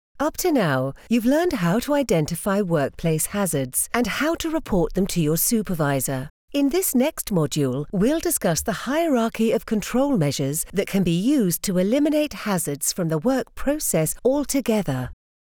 Velvety, sensual, sophisticated, intriguing in a natural RP.